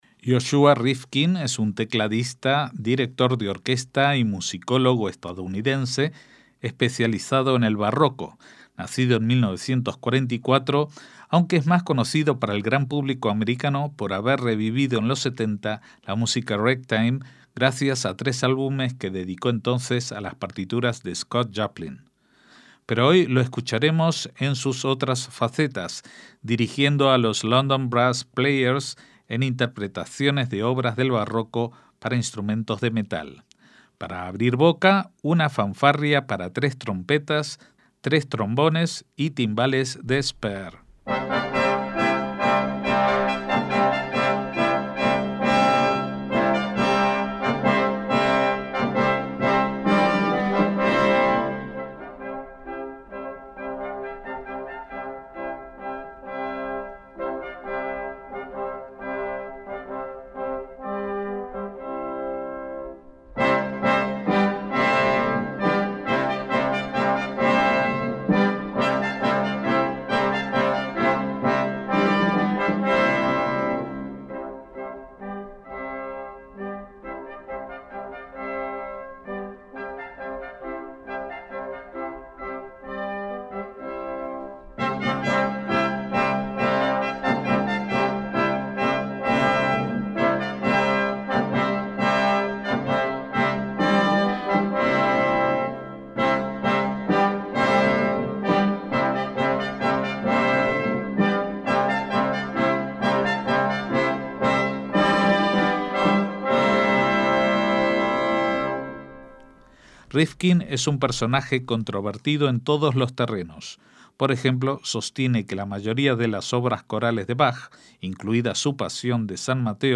MÚSICA CLÁSICA
varias piezas del Renacimiento y Barroco